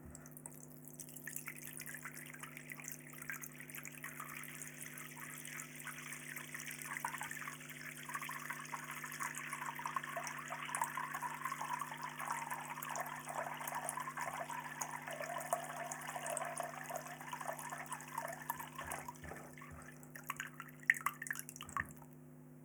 Sink Filling Up Sound
household
Sink Filling Up